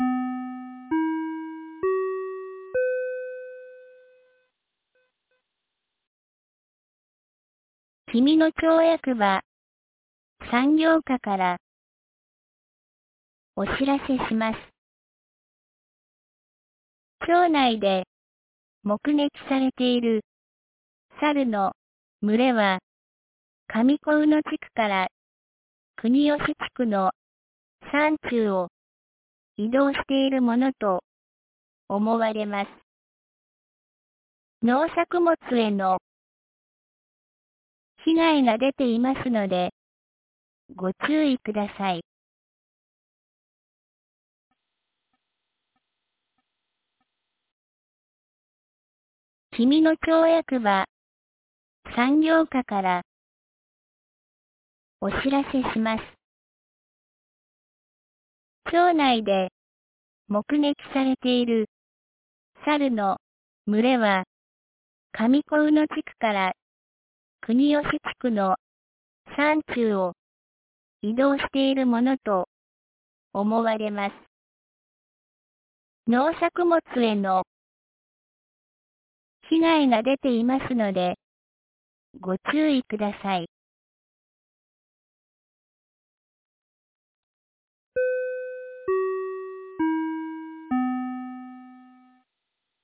2025年09月04日 17時06分に、紀美野町より上神野地区、下神野地区、国吉地区へ放送がありました。